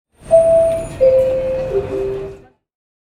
Attention Bell Sound Effect
Description: Attention bell sound effect. Bell sound effect that announces the arrival of a train in a metro or railway station.
Attention-bell-sound-effect.mp3